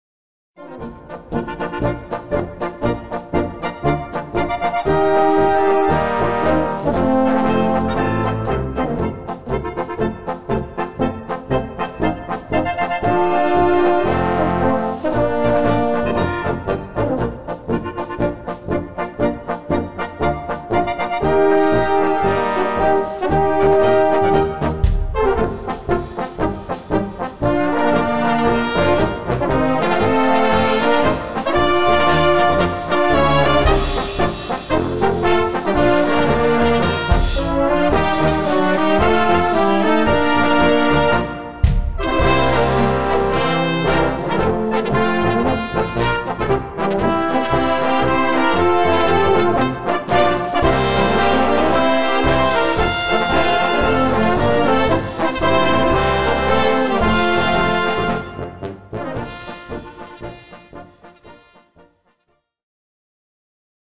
Polka
Blasorchester
Hier kommen alle Register zur Geltung.